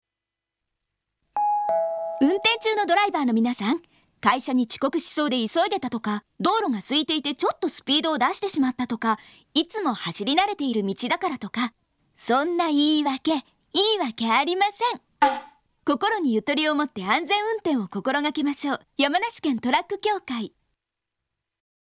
ラジオコマーシャル